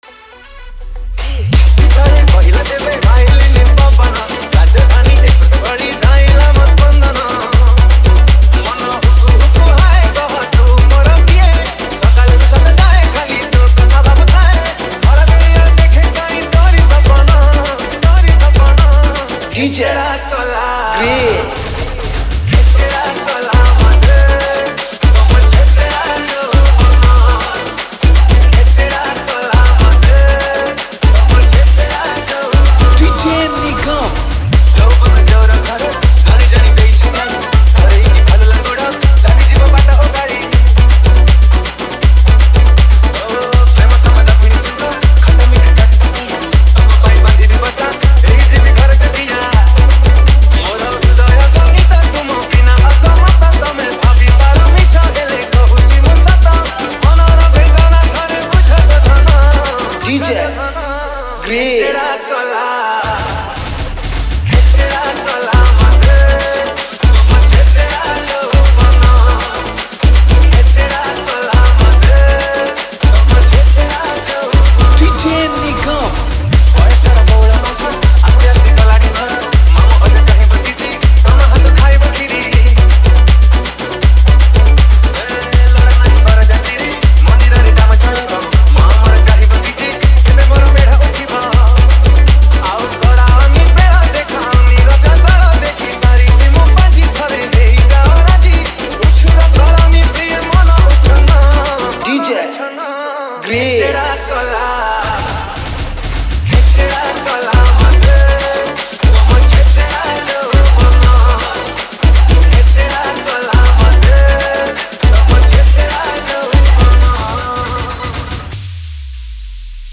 Tapori Mix